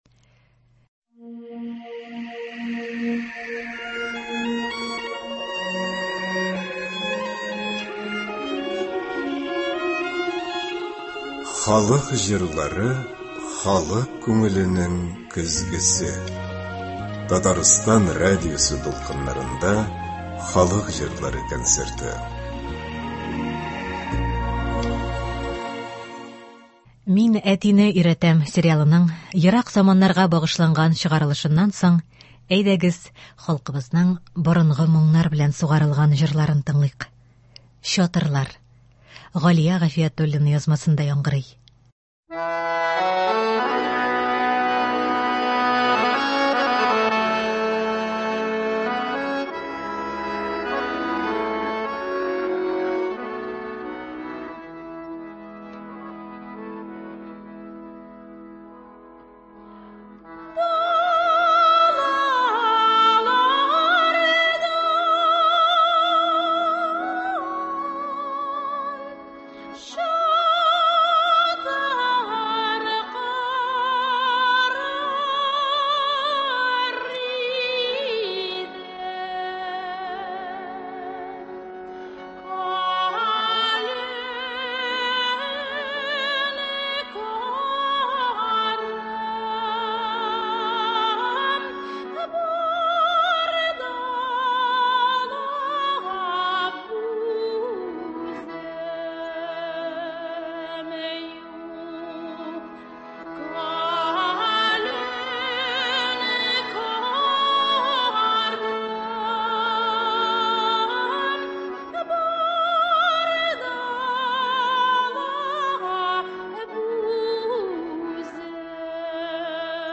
Татар халык көйләре (16.12.23)
Бүген без сезнең игътибарга радио фондында сакланган җырлардан төзелгән концерт тыңларга тәкъдим итәбез.